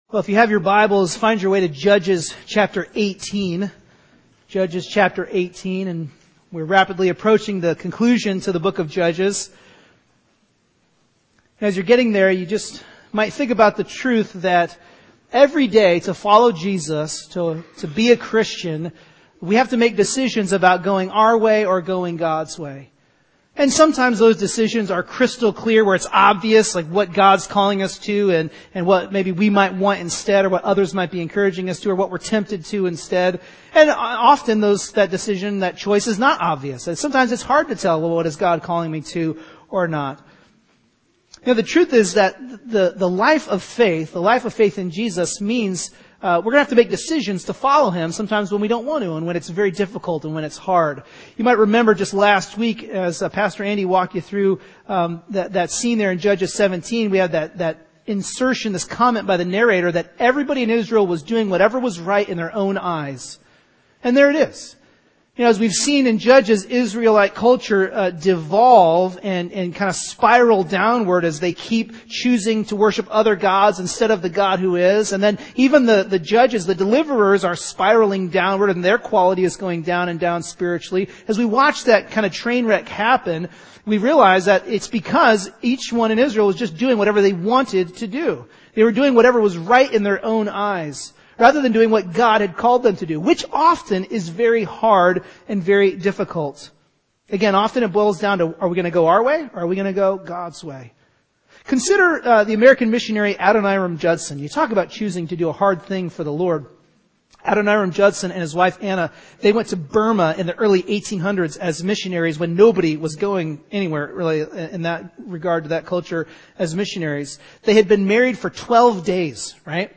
GPBC-AM-Sermon-2-28-16.mp3